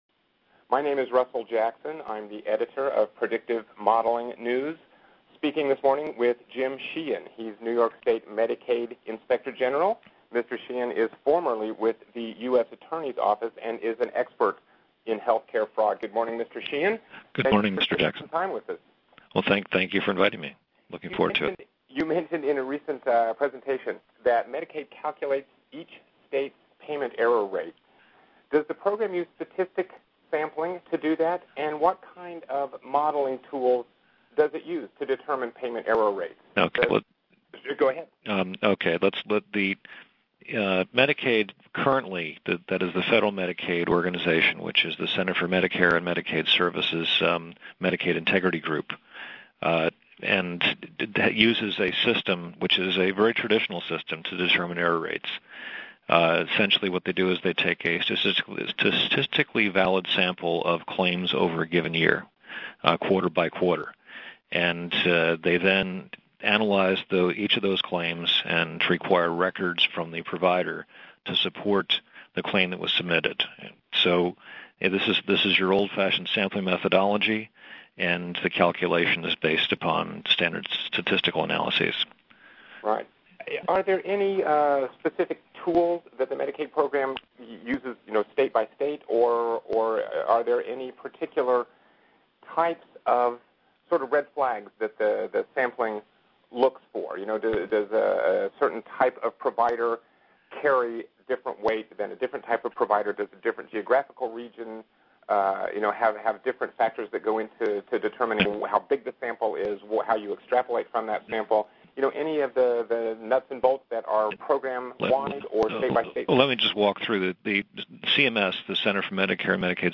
Medicaid Expert Details New Uses for PM in Ensuring Program Compliance: featuring excerpts of an interview with Jim Sheehan, New York State�s Medicaid Inspector General.